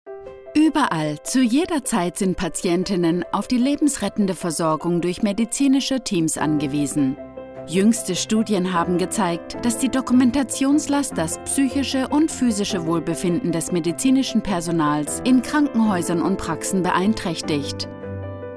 FEMALE VOICE OVER TALENT
0123Medical_German.mp3